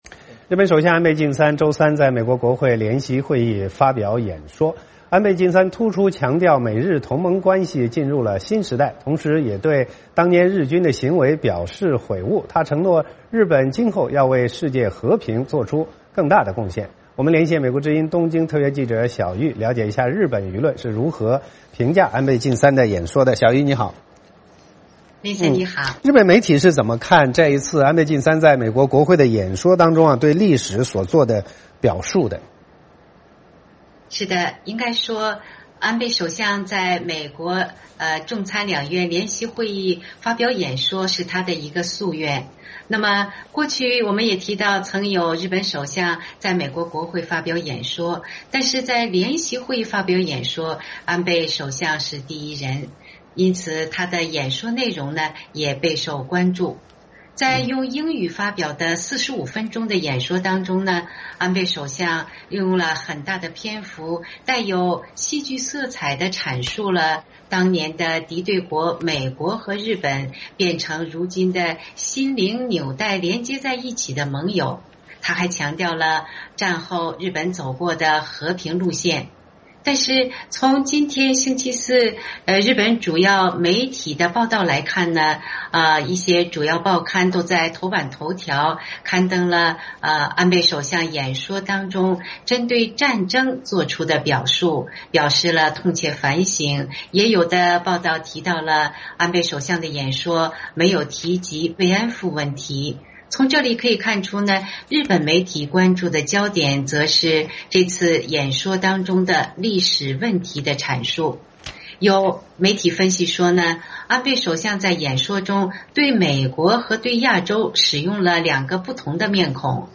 VOA连线：日本舆论评说安倍美国演讲